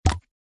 SeedlingHittingWalls.ogg